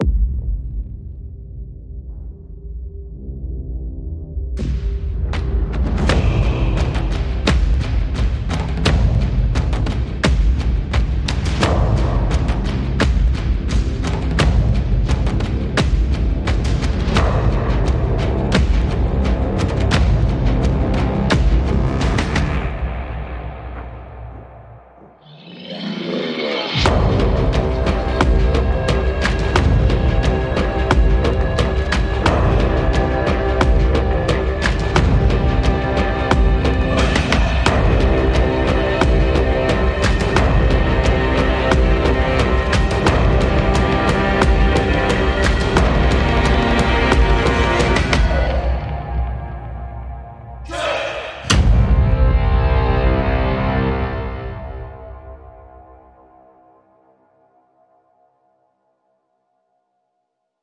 - 大鼓、小鼓、军鼓、鼓组、鼓圈、鼓边等各种鼓类乐器
- 钟琴、木琴、钢片琴、管钟等各种键盘类乐器
- 镲、锣、铃、铜钹等各种金属类乐器
- 拍手、拍腿、拍胸等各种人声类乐器
- 水晶杯、玻璃瓶、破碎玻璃等各种特殊类乐器